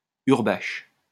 Hurbache (French pronunciation: [yʁbaʃ]